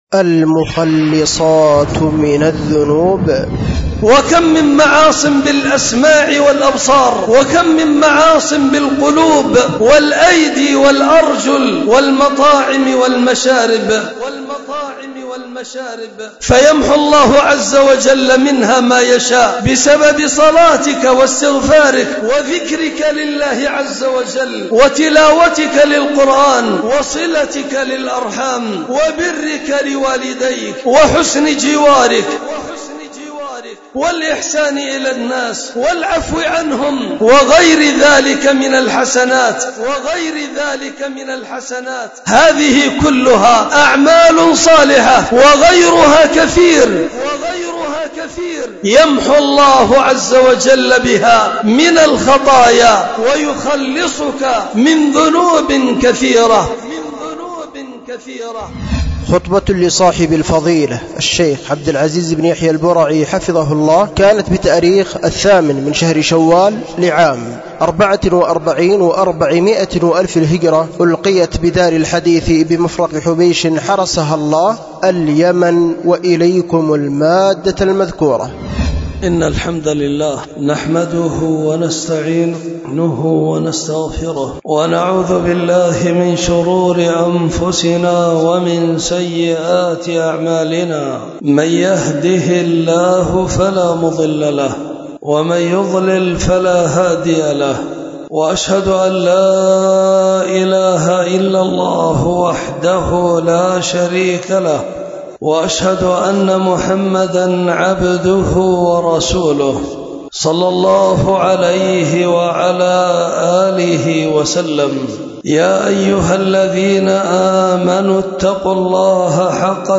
خطبة
بدار الحديث بمفرق حبيش